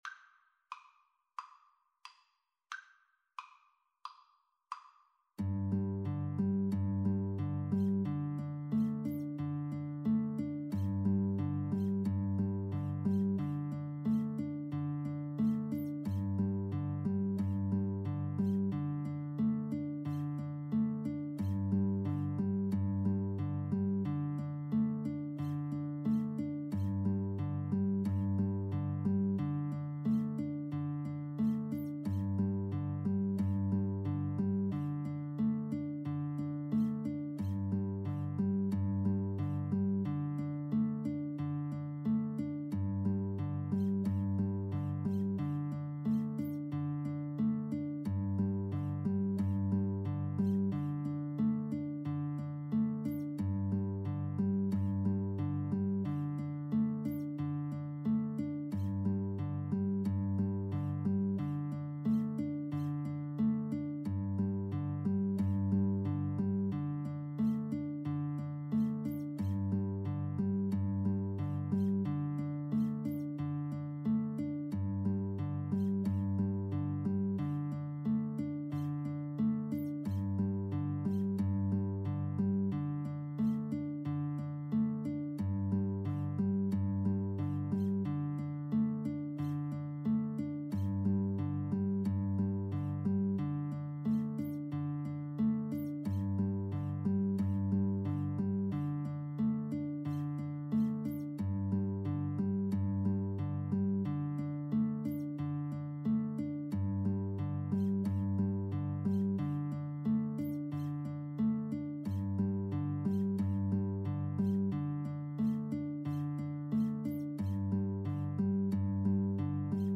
Anonymous early renaissance piece.
A minor (Sounding Pitch) (View more A minor Music for Mandolin-Guitar Duet )
Mandolin-Guitar Duet  (View more Intermediate Mandolin-Guitar Duet Music)
Classical (View more Classical Mandolin-Guitar Duet Music)